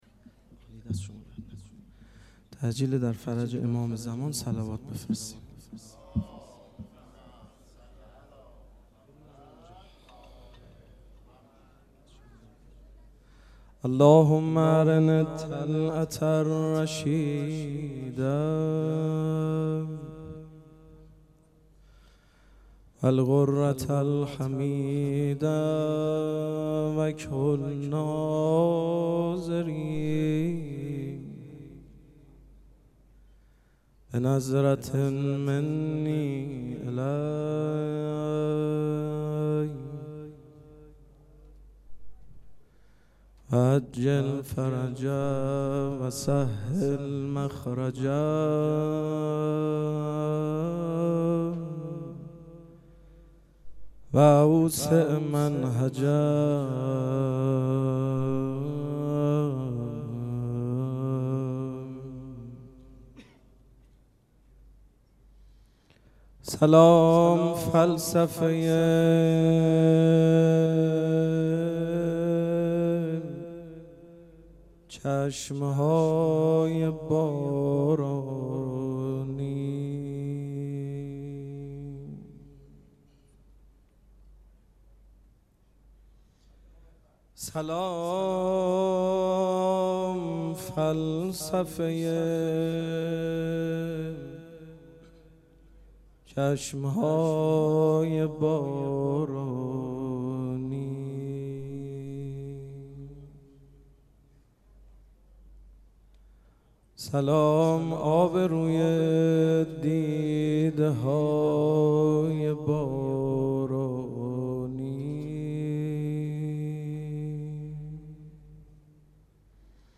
ذکر توسل
مراسم عزاداری شب شهادت حضرت رقیه سلام الله علیها
پیش منبر